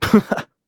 Kibera-Vox_Happy1.wav